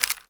Sfx_creature_trivalve_scuttle_slow_front_legs_01.ogg